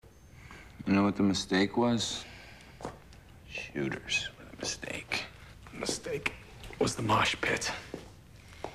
The three agents talking about the mistakes at the last night's party.